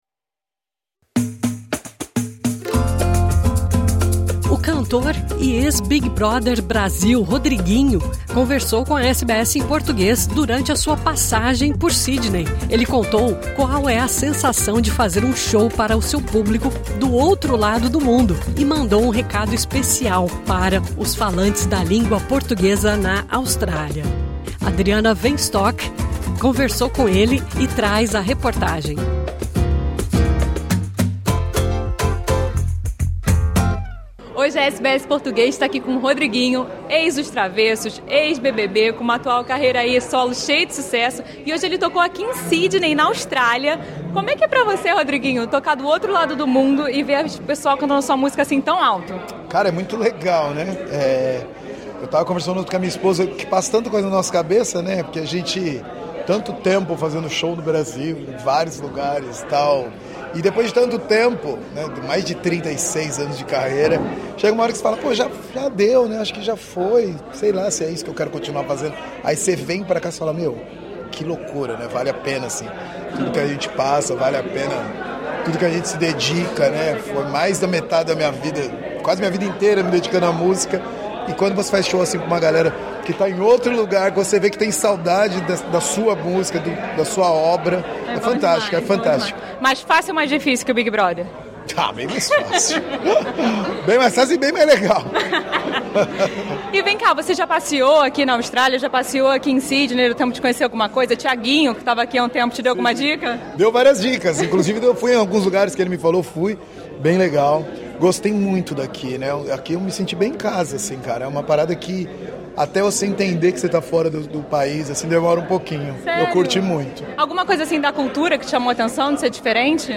Conversamos com exclusividade com o cantor Rodriguinho durante sua passagem pela Austrália. O ex-integrante da banda Os Travessos compartilhou a sensação de se apresentar para seu público do outro lado do mundo, revelou os próximos passos de sua carreira e mandou um recado para os brasileiros na Austrália.